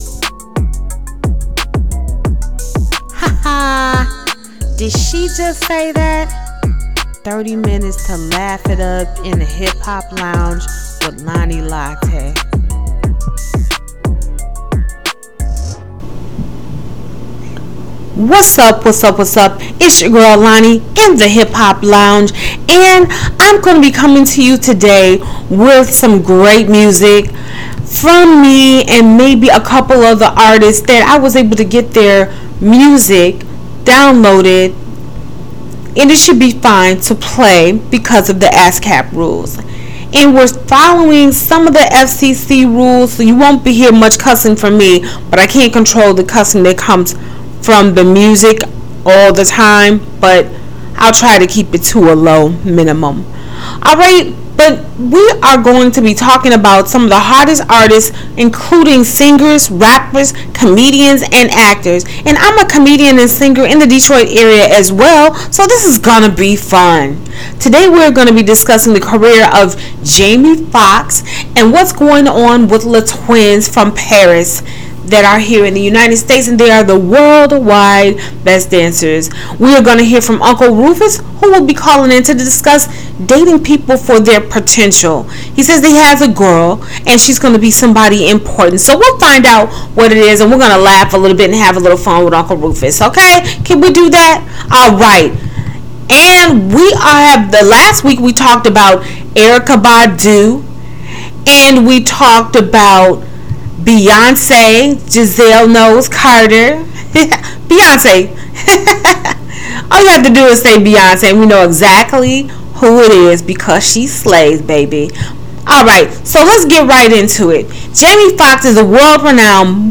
Get ready to feed your R&B and hip hop music hunger.
Welcome to Hip Hop Lounge: Your weekly dose of music, talk and laughs.